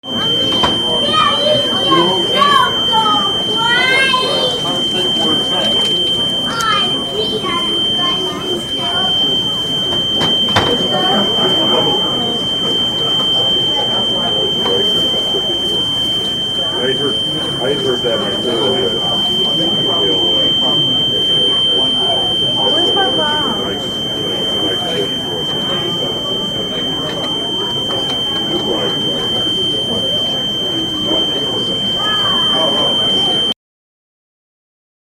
Fire alarm sounds (siren)